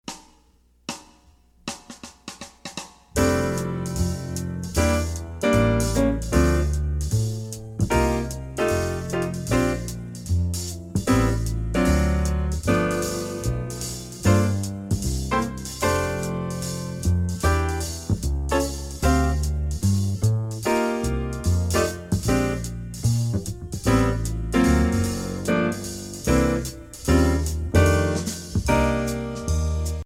Voicing: Tenor Sax